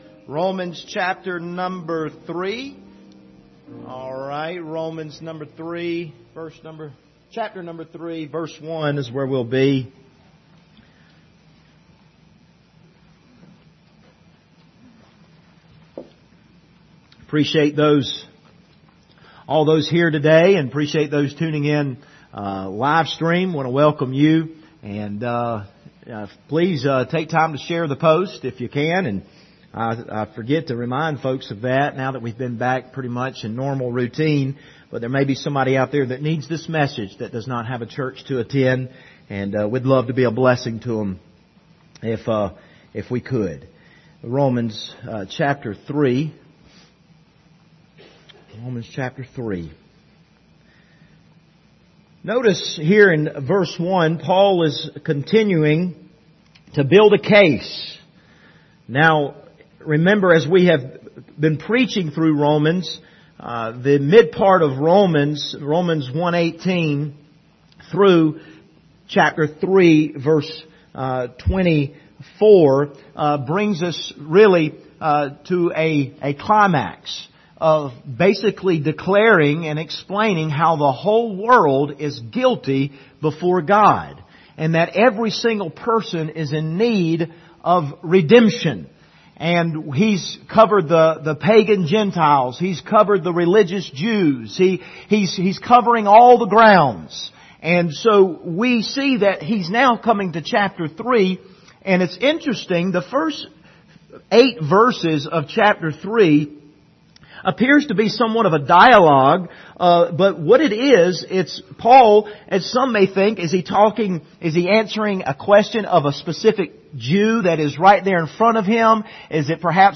The Just Shall Live By Faith Passage: Romans 3:1-8 Service Type: Sunday Morning Topics